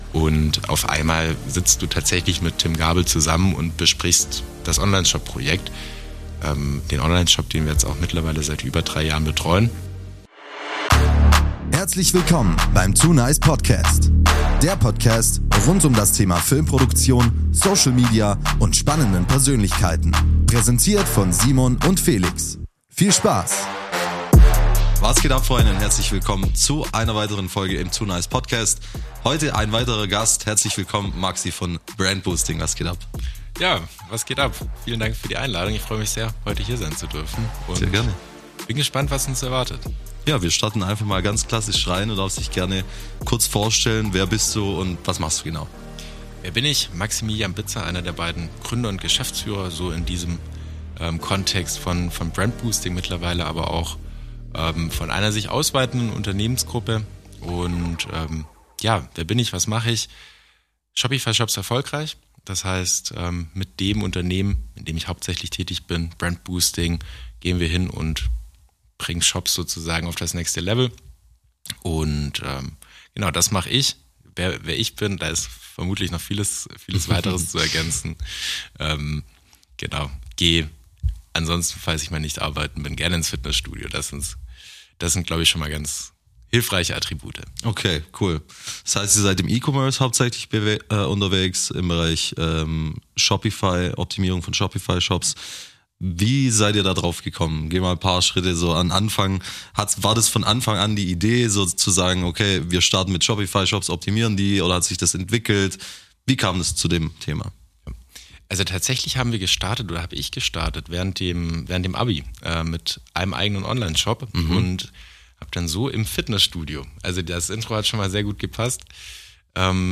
In dieser Folge erwartet dich ein inspirierendes Gespräch, das zeigt, wie aus Visionen echte Erfolgsgeschichten werden.